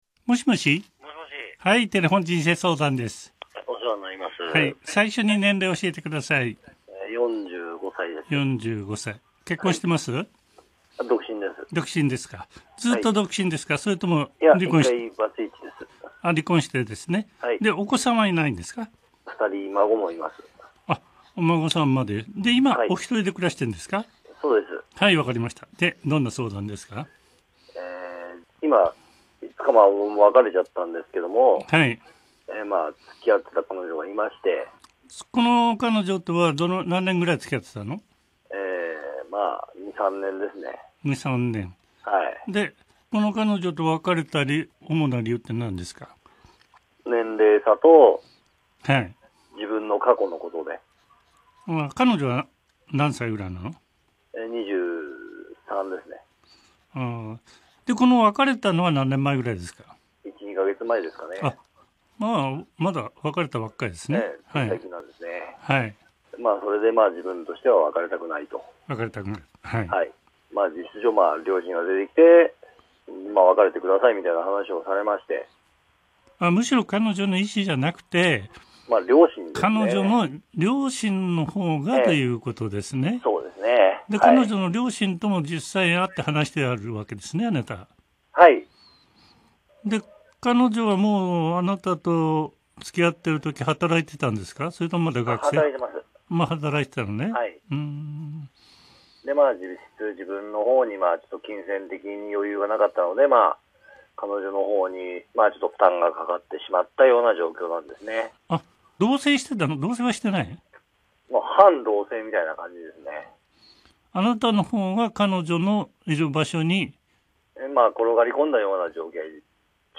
前科者が２２歳差婚を反対され励まされて泣く。